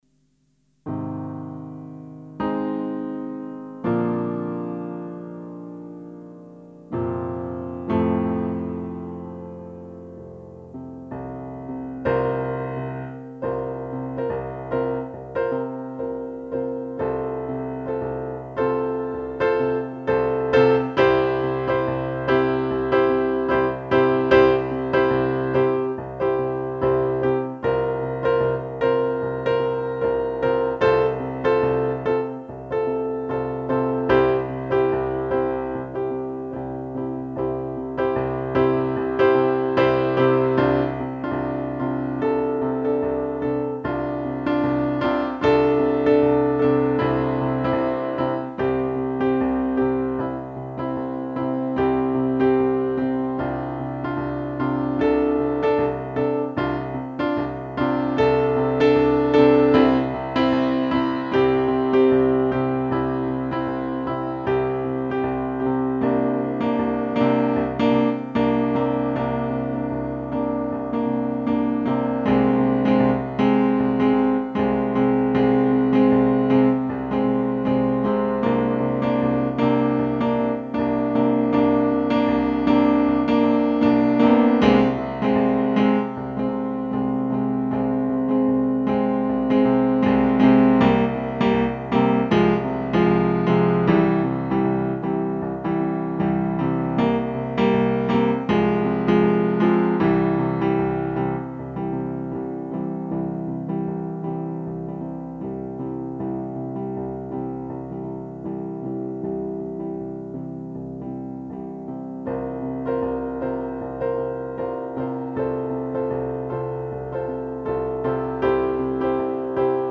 Tempo: 76 bpm / Date: 01.11.2013